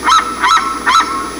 seriema.wav